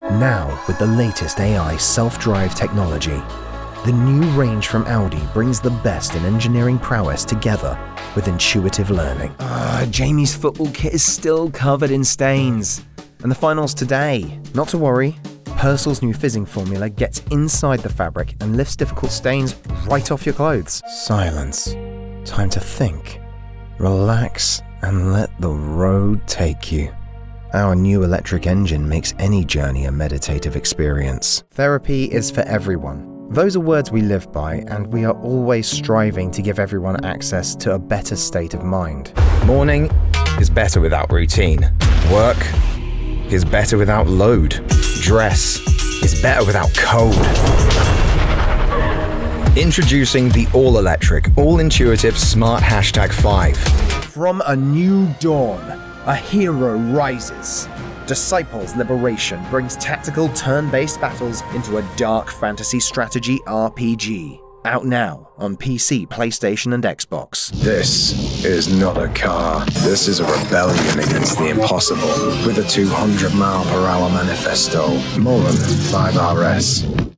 Engels (Brits)
Commercieel, Veelzijdig, Vriendelijk, Natuurlijk, Warm
Commercieel